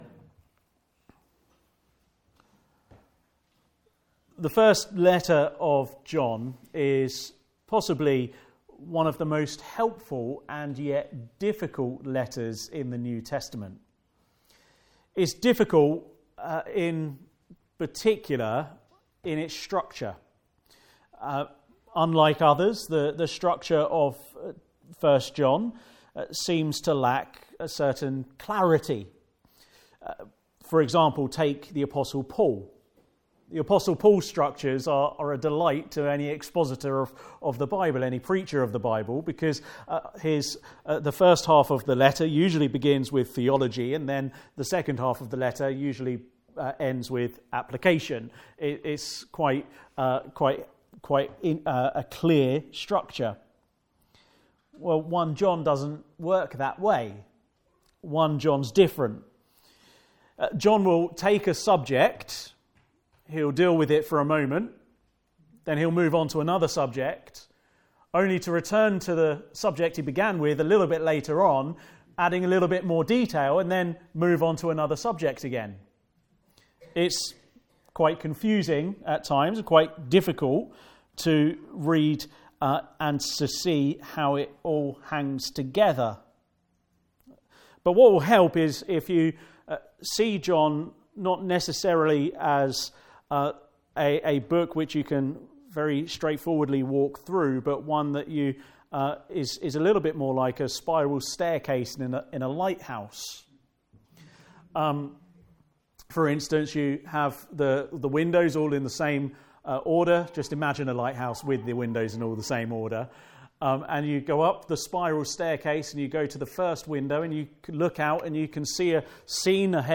Passage: 1 John 1: 1-10 Service Type: Afternoon Service